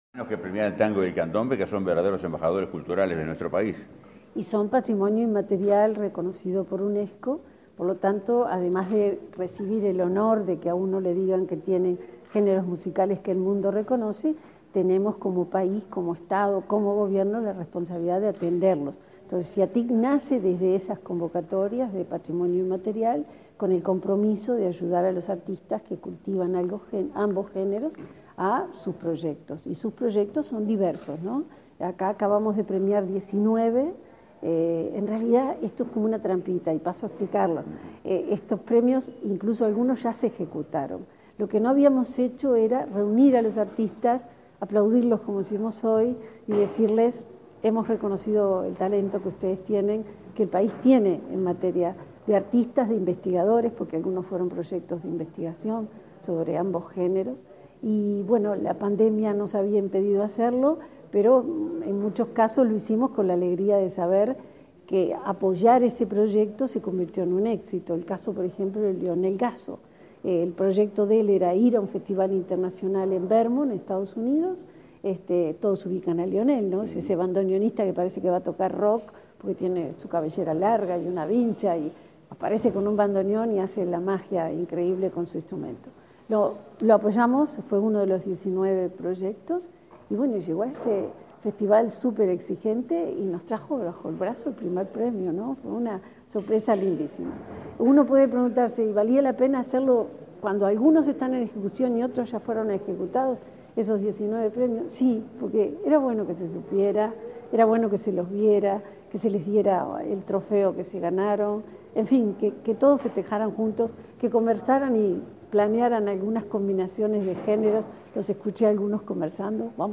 Declaraciones a la prensa de la subsecretaria del MEC
Declaraciones a la prensa de la subsecretaria del MEC 06/09/2022 Compartir Facebook X Copiar enlace WhatsApp LinkedIn La vicecanciller, Carolina Ache, y la subsecretaria de Educación y Cultura, Ana Ribeiro, participaron en el reconocimiento a 20 proyectos de tango y candombe, en el Palacio Taranco de Montevideo. Luego, Ribeiro dialogó con la prensa.